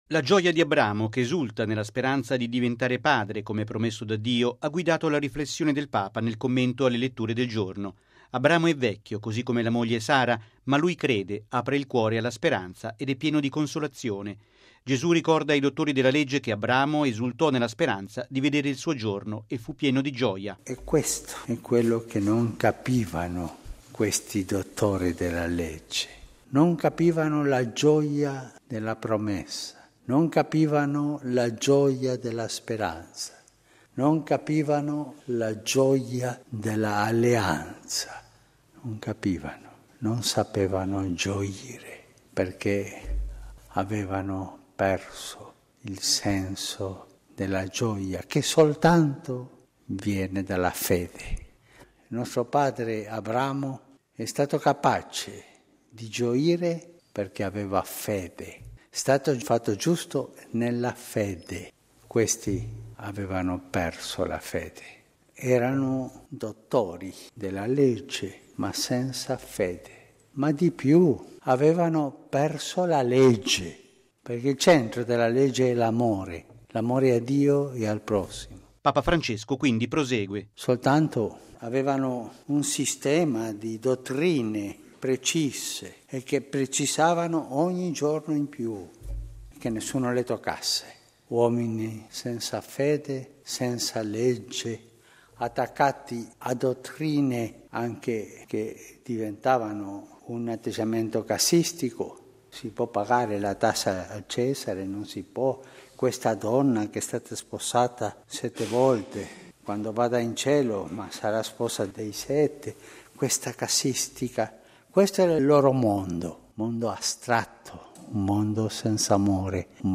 Non è la dottrina fredda che dà gioia, ma la fede e la speranza di incontrare Gesù. E’ triste un credente che non sa gioire: è quanto ha detto il Papa nell’omelia pronunciata nella Messa del mattino a Santa Marta.